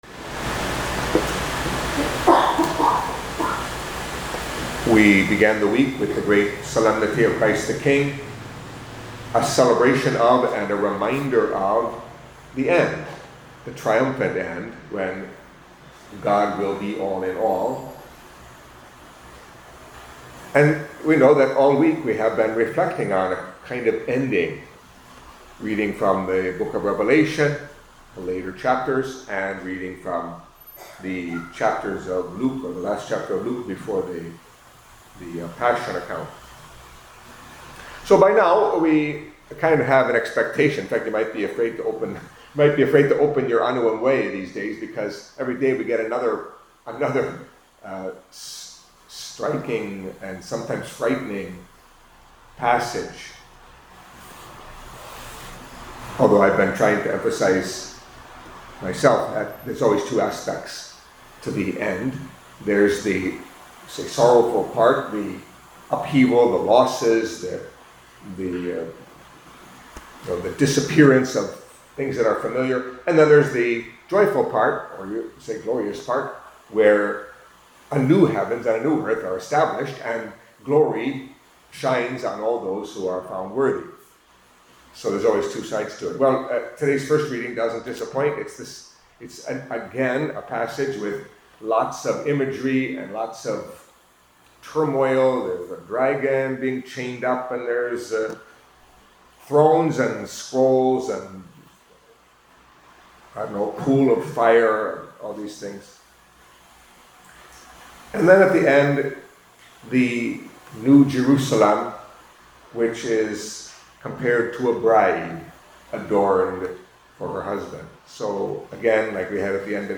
Catholic Mass homily for Friday of the Thirty-Fourth Week in Ordinary Time